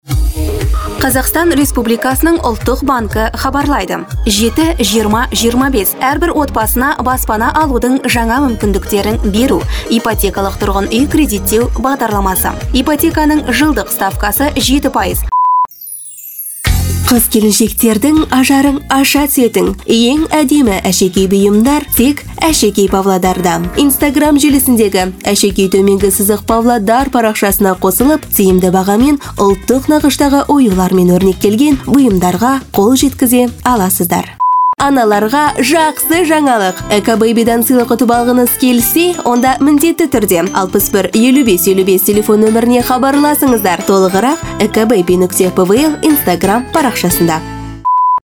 Теледидар жүргізушілерінің дауыстары
Әйелдер